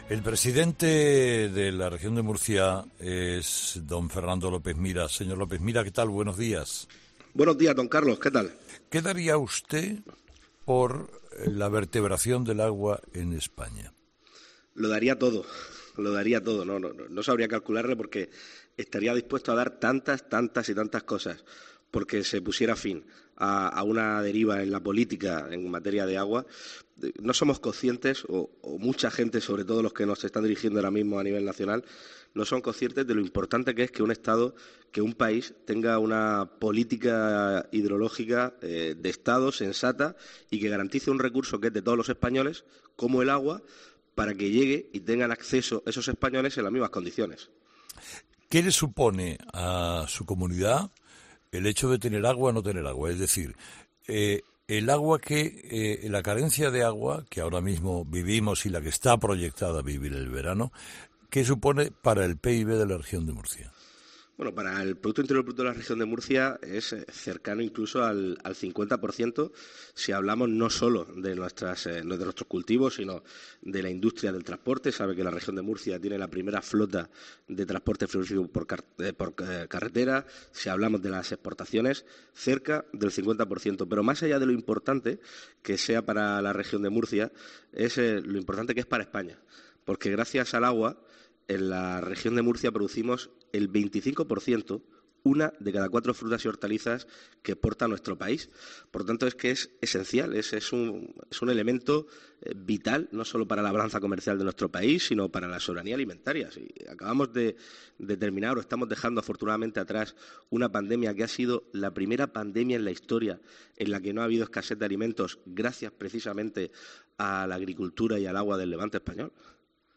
Carlos Herrera entrevista al presidente de la Región de Murcia, Fernando López Miras, que explica cómo se vive en la comunidad que preside la falta de agua y las perspectivas de cara a los próximos meses.